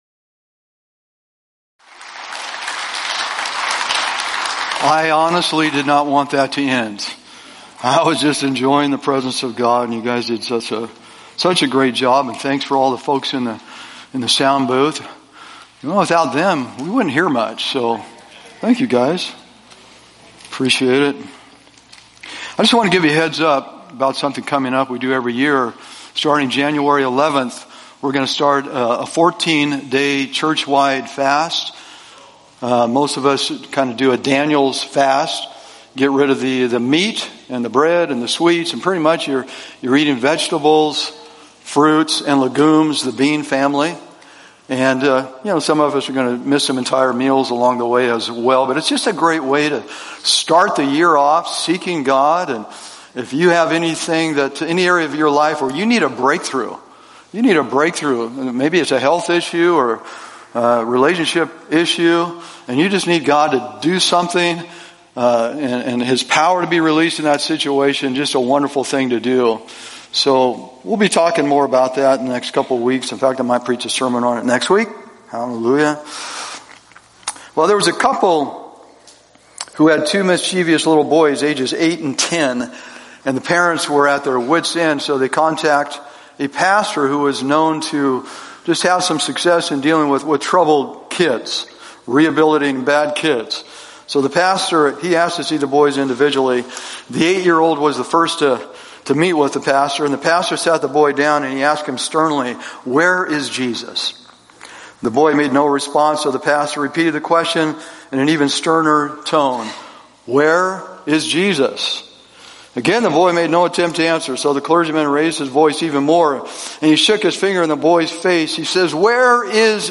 Sermons | Covenant Christian Church